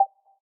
volume_key.ogg